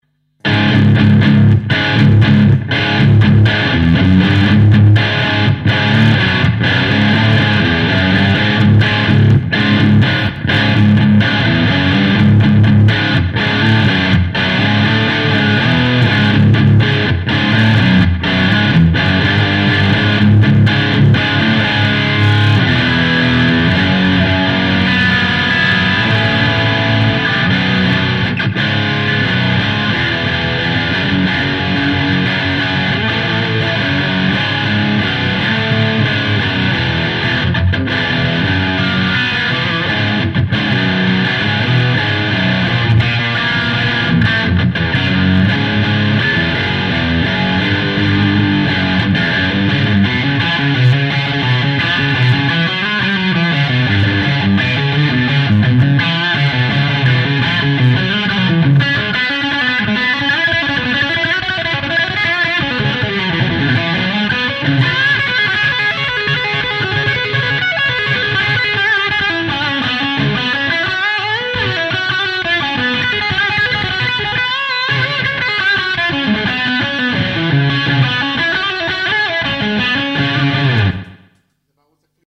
The_Sabre_low_ovd_2_demomp3.wav